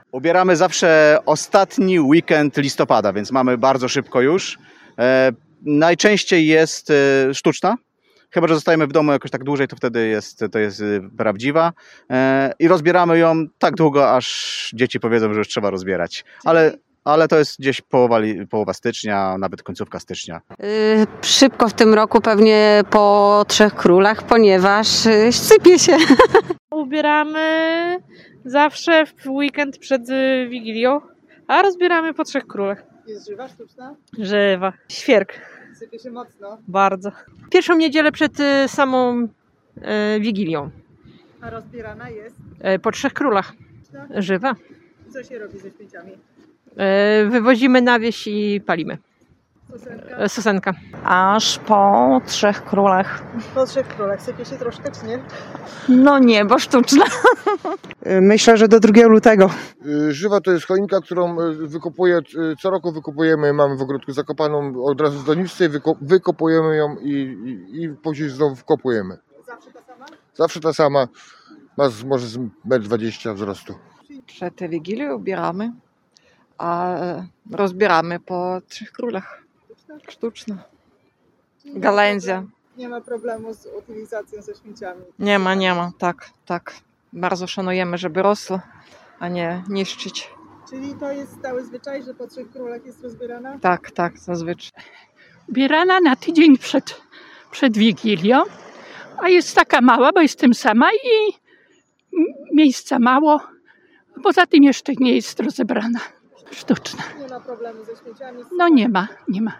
Co i kiedy zrobią z choinkami rozmówcy Radia 5 spotkani na suwalskich ulicach?